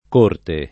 corte [
k1rte] s. f. — anche top. o elem. di toponimi variam. composti: Corte Franca (Lomb.), Corte de’ Frati (id.), Cortemaggiore (E.-R.), Monchio delle Corti (id.), ecc.